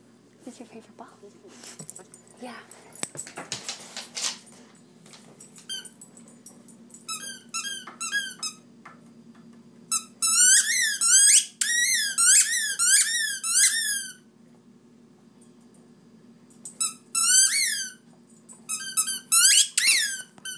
Playing with her squeaky ball